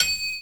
Ride 11.wav